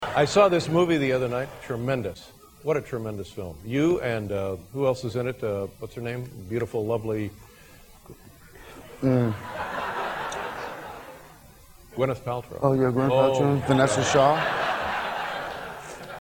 Tags: Joaquin Phoenix Joaquin Phoenix on Drugs Joaquin Phoenix on David Letterman Joaquin Phoenix interview funny clip